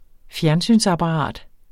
Udtale [ ˈfjæɐ̯nˌsyns- ]